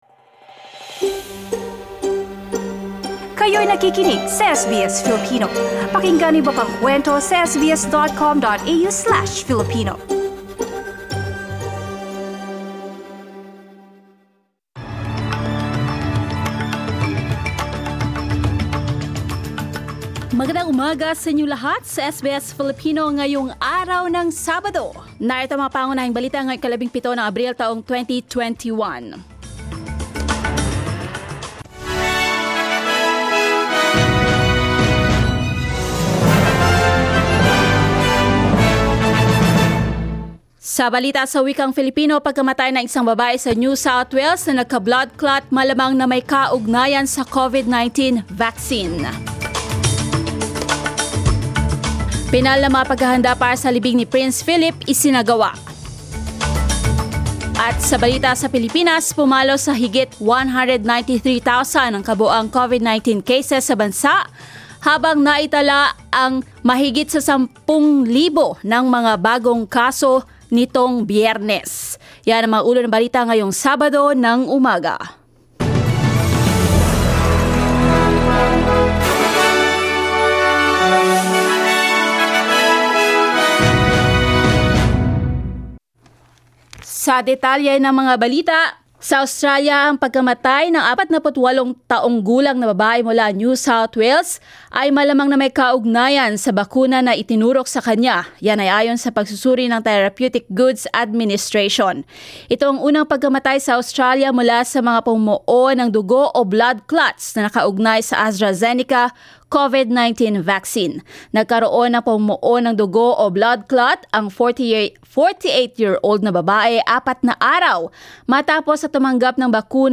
SBS News in Filipino, Saturday 17 April